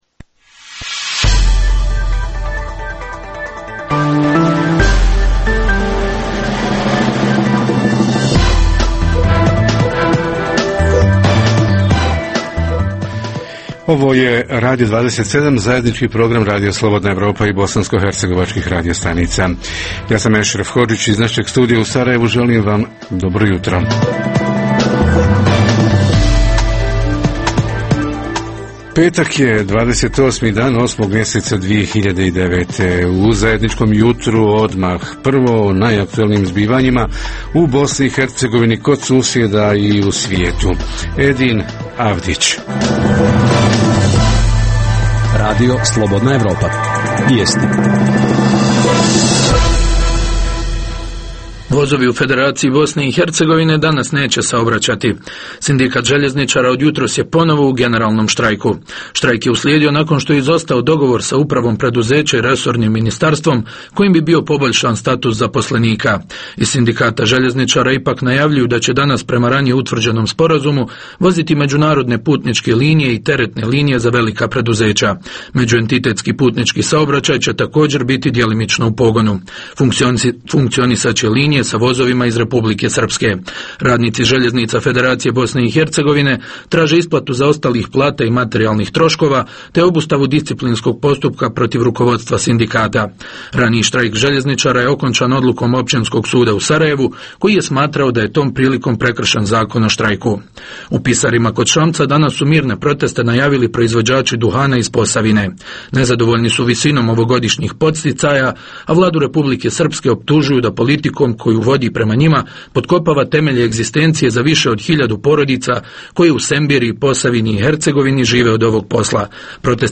Redovni sadržaji jutarnjeg programa za BiH su i vijesti i muzika.